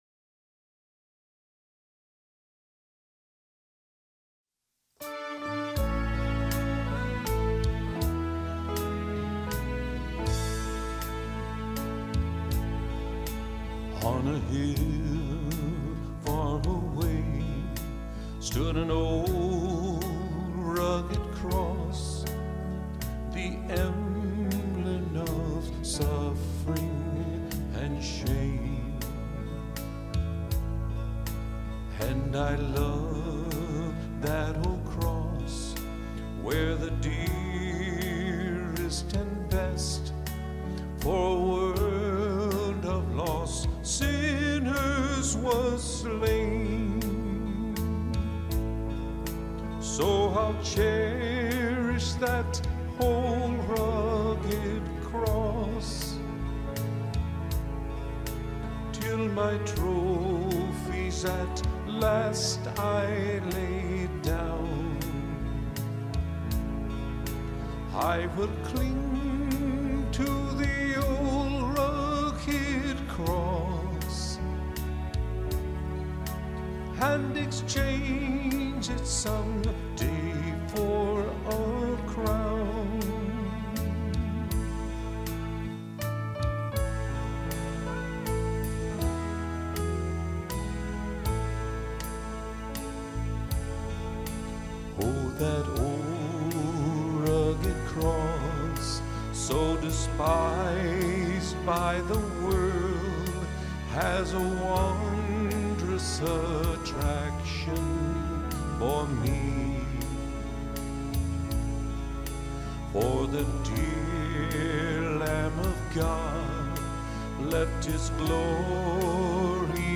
61 просмотр 53 прослушивания 2 скачивания BPM: 82